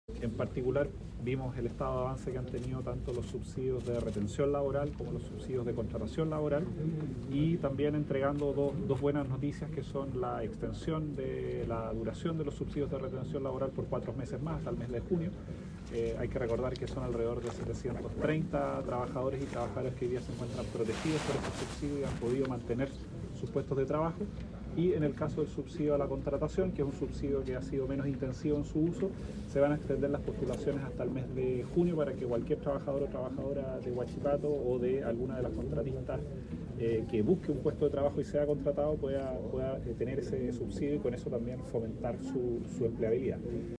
La cita tuvo lugar en el auditorio de la Cámara Chilena de la Construcción en Concepción y forma parte del despliegue integral del Gobierno para enfrentar el impacto económico tras el cierre de la Compañía Siderúrgica Huachipato.
Por su parte, el ministro del Trabajo, Giorgio Boccardo, anunció la extensión de la duración los subsidios de retención hasta el mes de junio, al igual que el subsidio a la contratación.